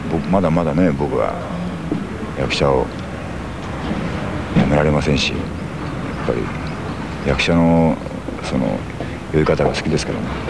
All sounds in WAV format are spoken by Yusaku himself (Also Andy Garcia and Michael Douglas).